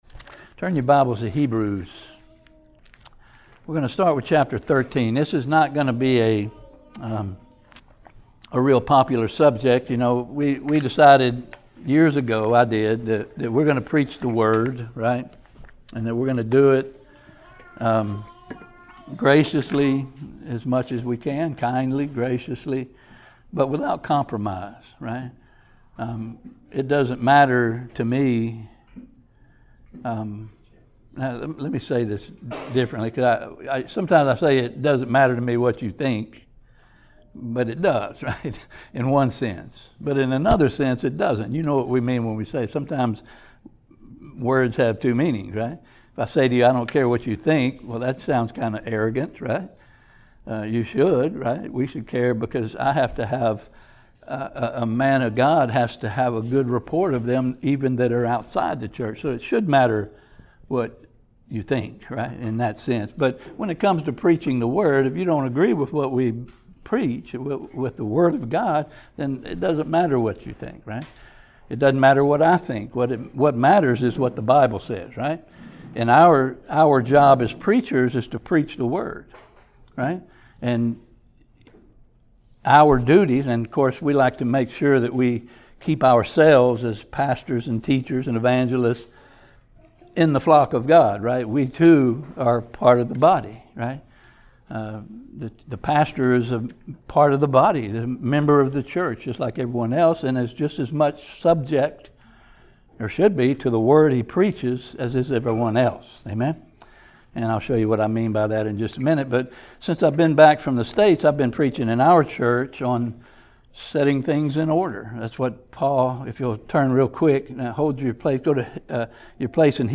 Passage: Hebrews 13:17 Service Type: Sunday Morning